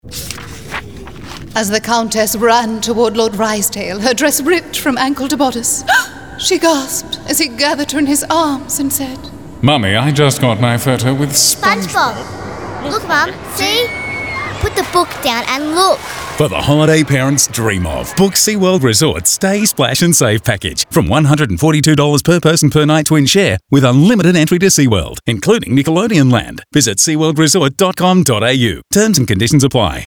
Here's a job I did for Sea World that I had a whale of a time writing. 0:30 SEA WORLD 'STAY SPLASH SAVE' RADIO - 'MUM' 0:30 SEA WORLD 'STAY SPLASH SAVE' RADIO - 'DAD'